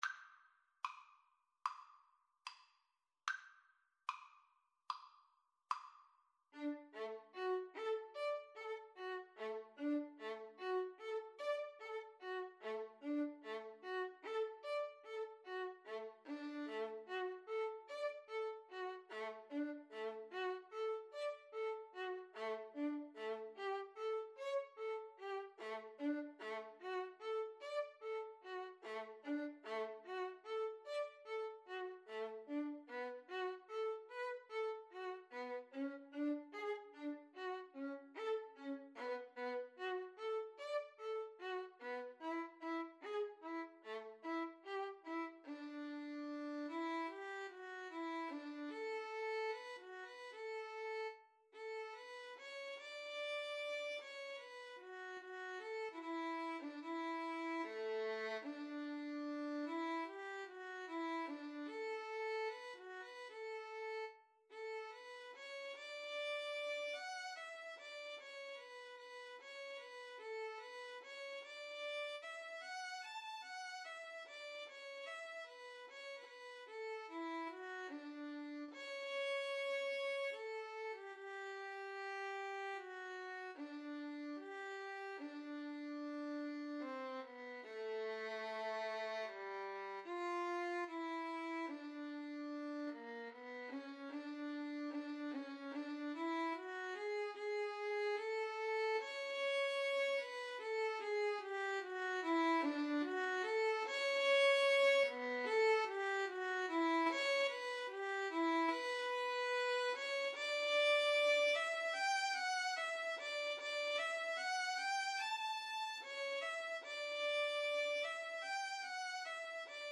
Classical (View more Classical Violin Duet Music)